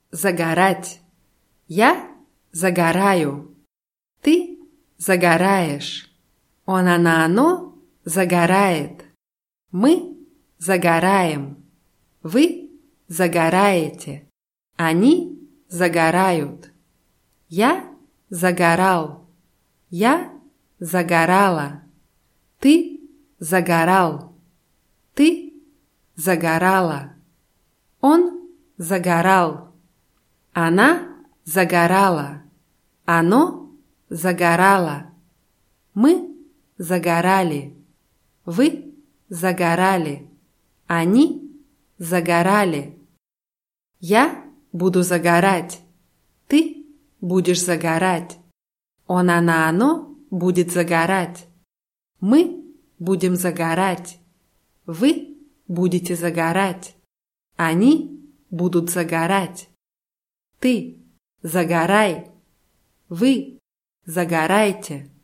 загорать [zagarátʲ]